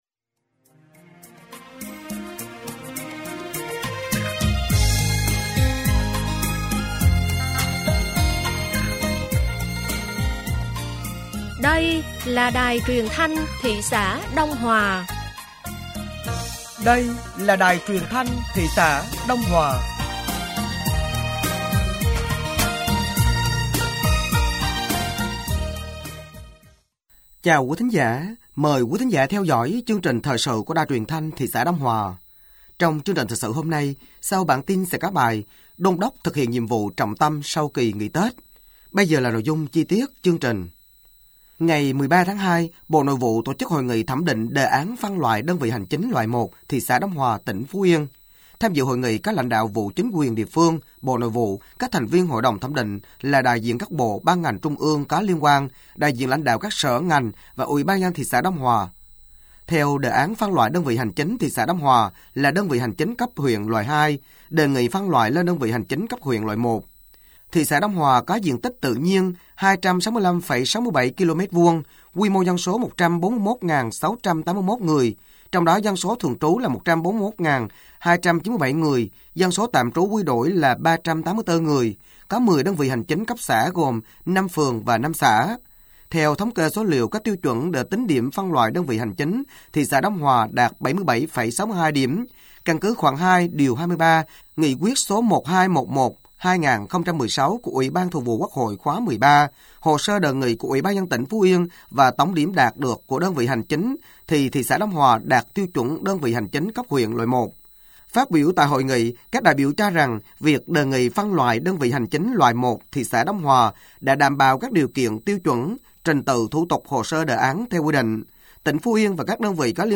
Thời sự tối ngày 14 và sáng ngày 15 tháng 02 năm 2025